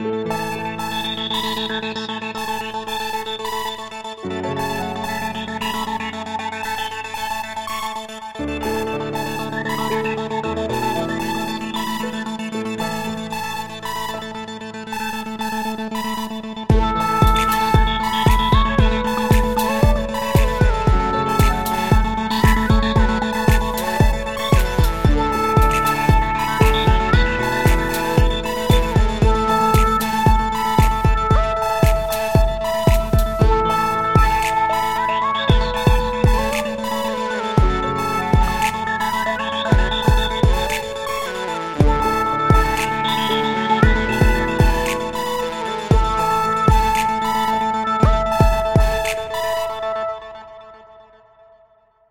Hip Hop / RnB / Poptrack!!!!!!